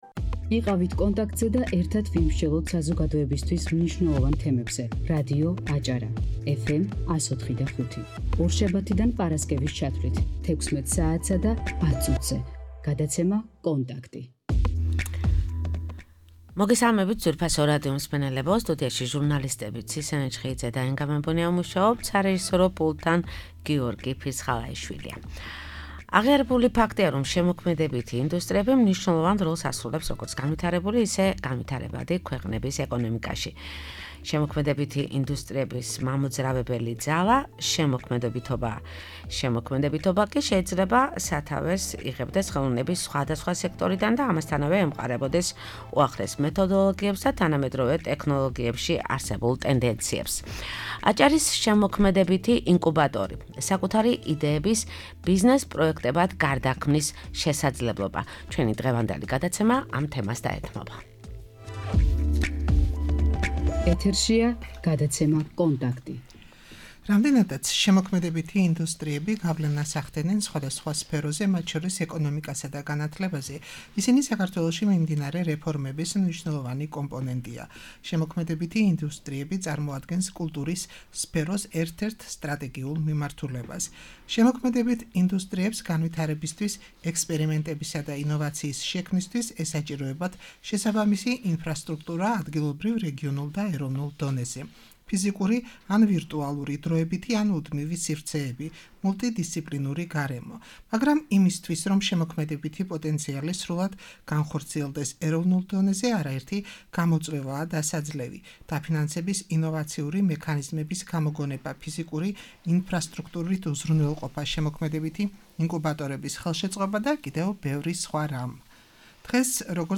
დღევანდელი, 24 დეკემბრის გადაცემის თემა იყო: ,,აჭარის შემოქმედებითი ინკუბატორი’’--- საკუთარი იდეების ბიზნეს პროექტებად გარდაქმნის შესაძლებლობა. "კონტაქტის" სტუდიის სტუმრები